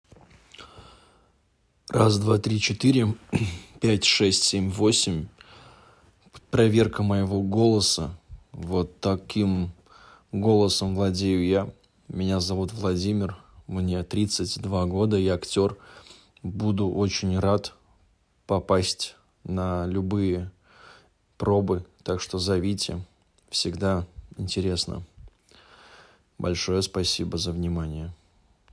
Проба голоса.m4a